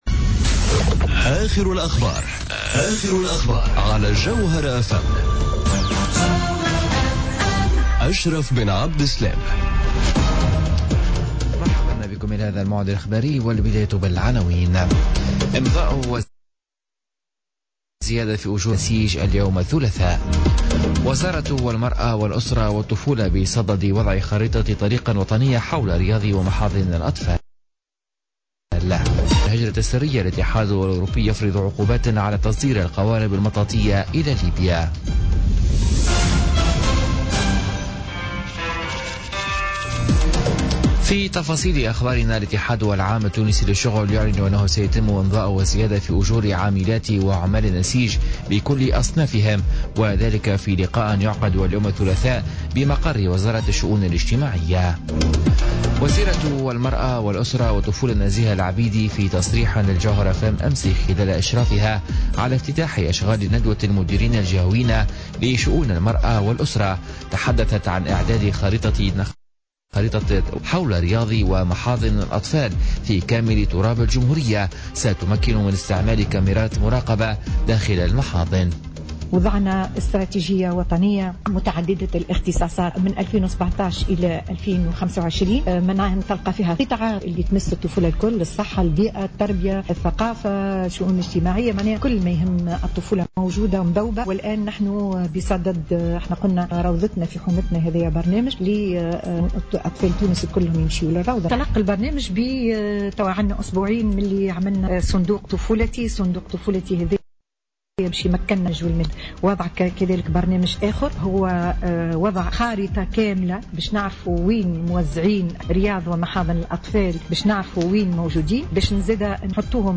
نشرة أخبار منتصف الليل ليوم الثلاثاء 18 جويلية 2017